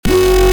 Vortex_OS_Stabs_10_F#
Vortex_OS_Stabs_10_F.mp3